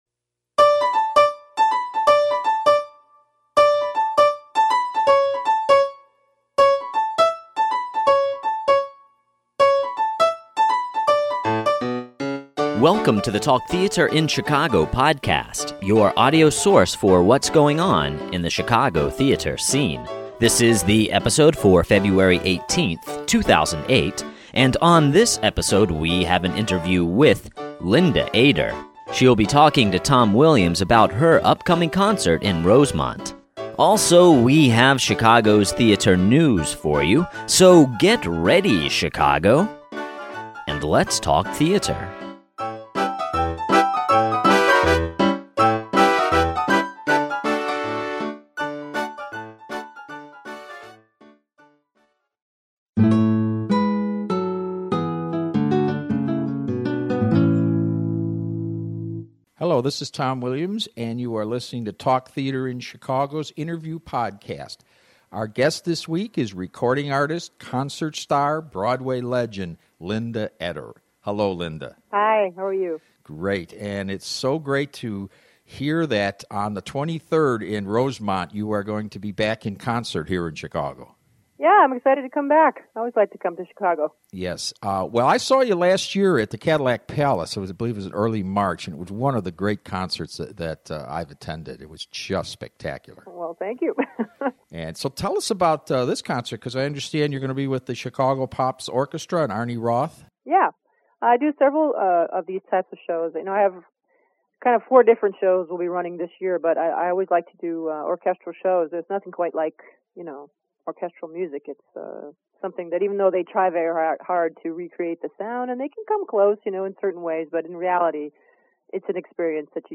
Linda Eder Interview Podcast
An interview with Linda Eder, the star concert and broadway vocalist. She talks about her upcoming show at the Rosemont Theatre as well as her career and her time on Broadway.